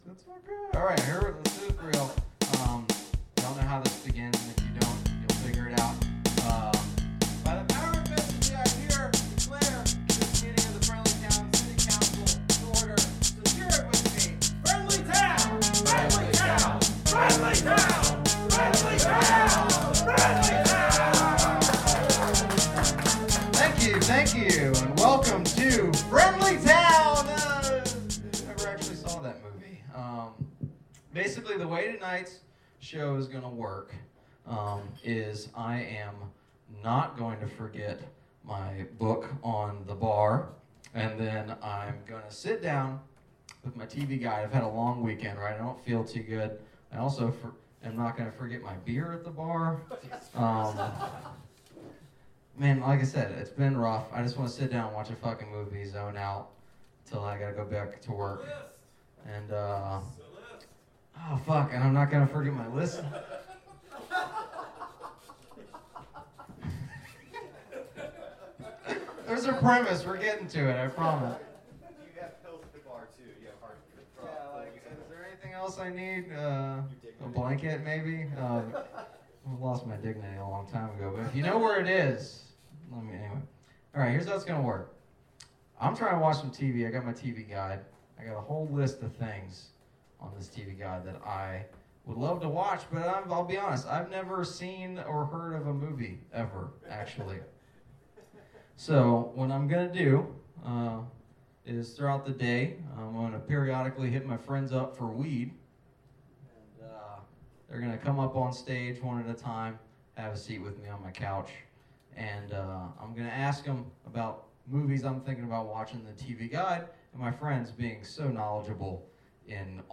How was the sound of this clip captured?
Recorded Live at the Pilot Light August 7, 2017, Knoxville TN